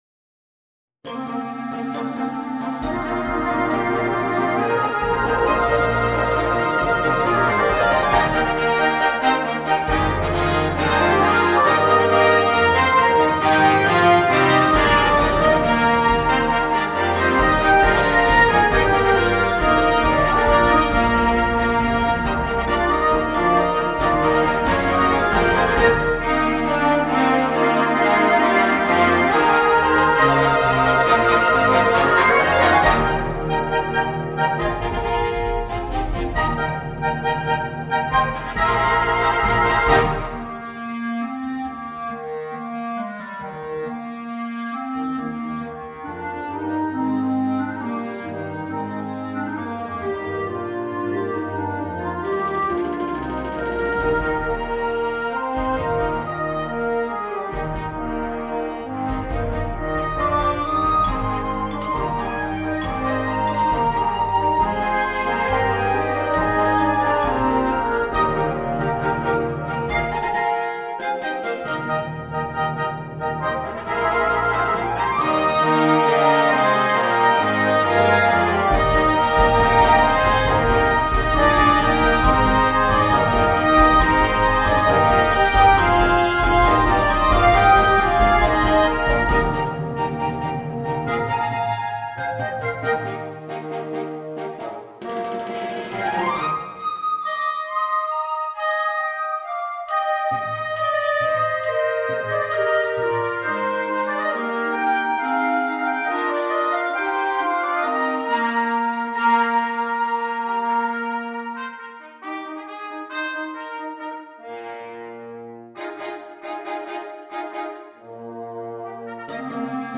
Gattung: Suite für Blasorchester
Besetzung: Blasorchester